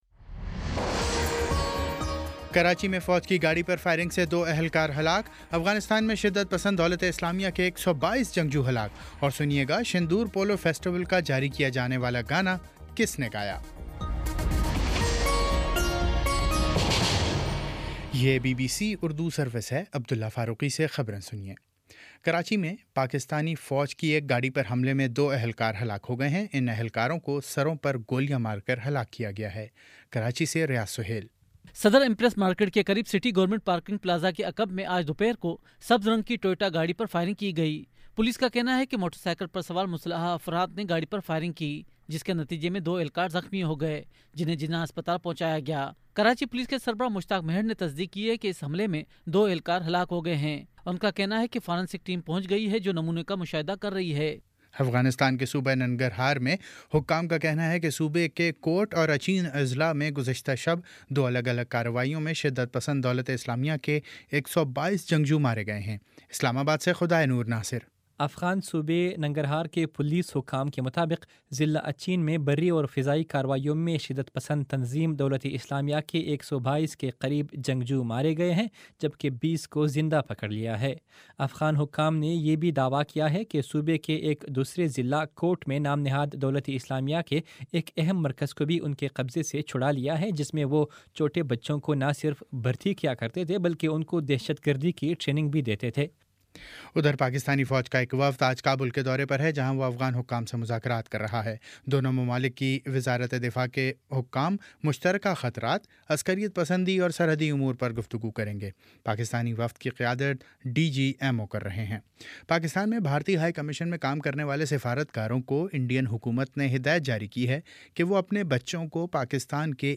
جولائی 26 : شام سات بجے کا نیوز بُلیٹن